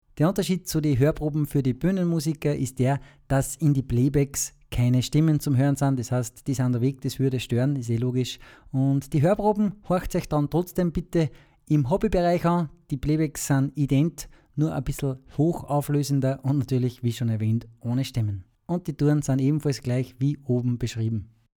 (Bühnen-Musiker)
• Gemütliches Tempo
• Original Tempo